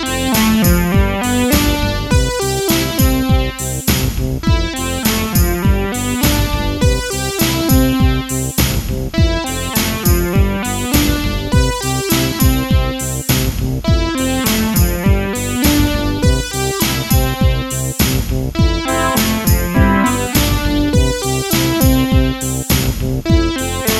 Remix Pop (1980s) 4:41 Buy £1.50